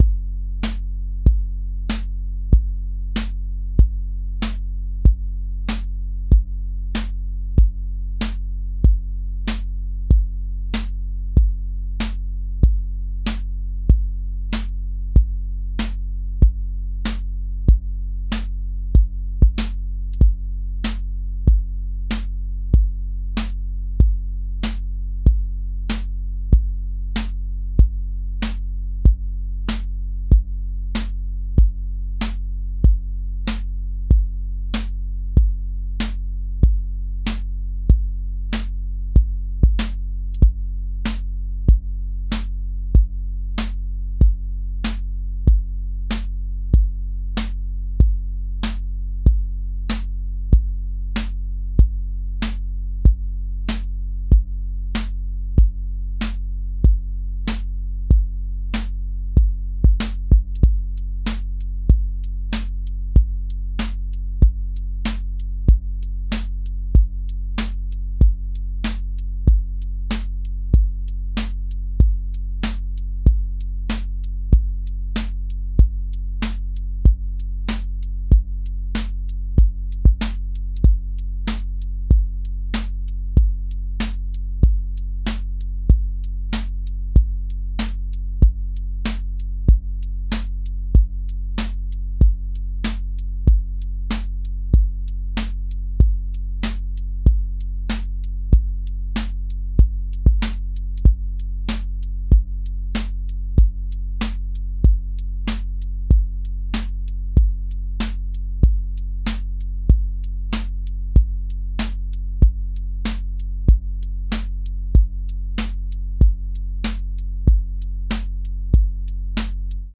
120-second Boom Bap
QA Listening Test boom-bap Template: boom_bap_drums_a
• voice_kick_808
• voice_snare_boom_bap
A 120-second boom bap song with a lifted loop section, a stripped verse section, a variant bridge, and a clear outro return. Use recurring drum, sub, and counter-rhythm patterns that recombine differently by section so the form feels like a song, not one loop.